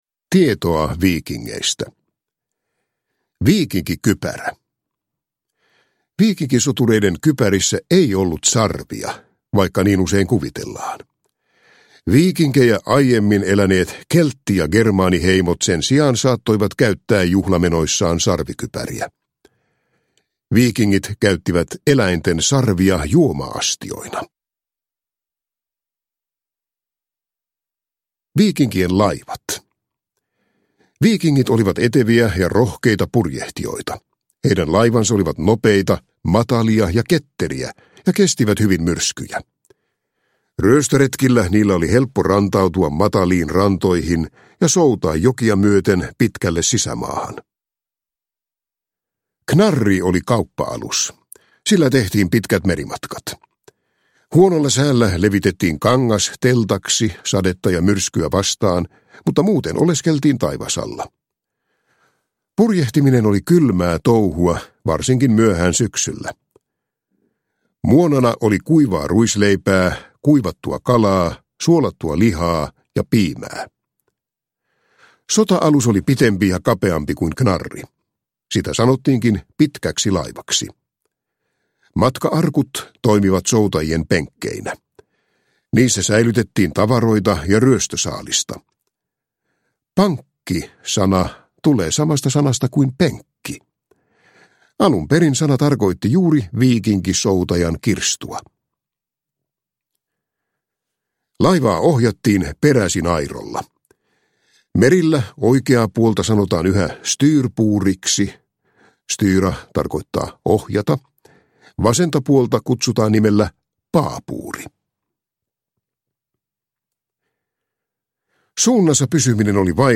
Viikingit tulevat! – Ljudbok – Laddas ner